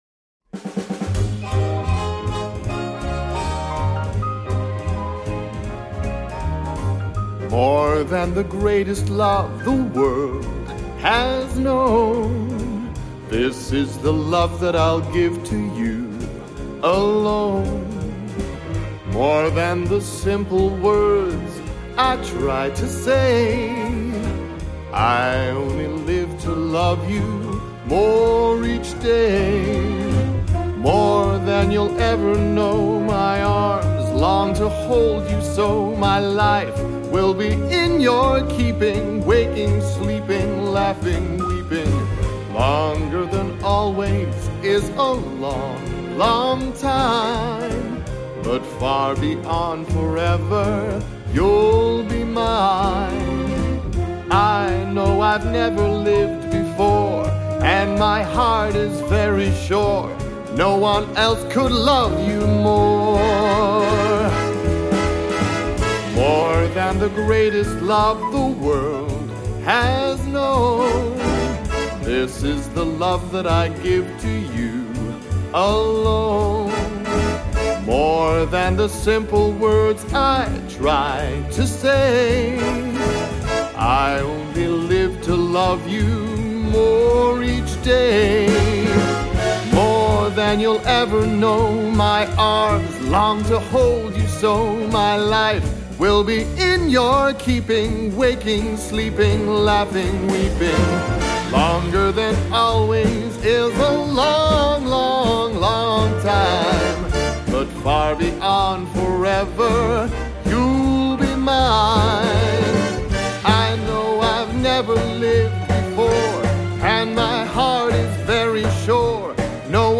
• Features a full-swing orchestra with lush strings